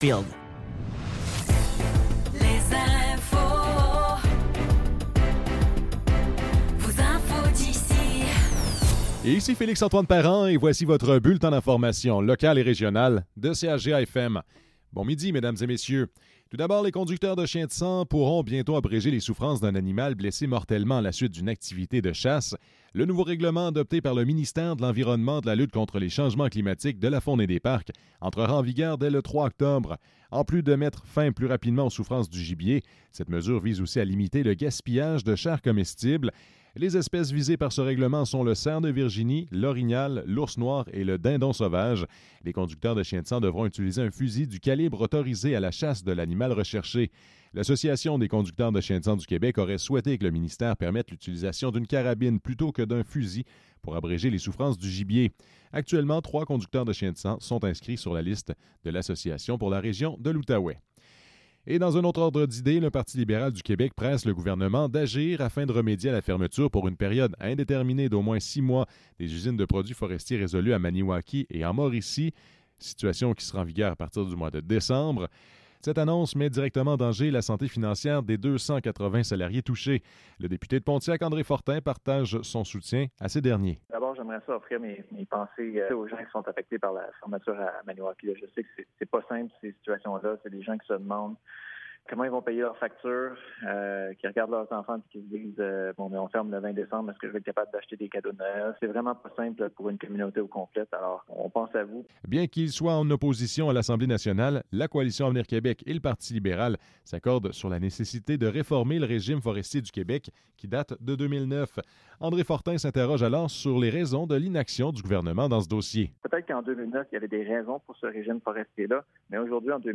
Nouvelles locales - 27 septembre 2024 - 12 h